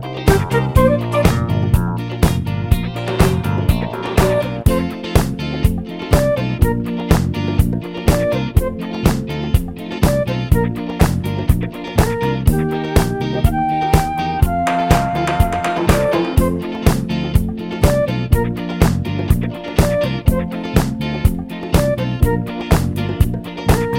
no Backing Vocals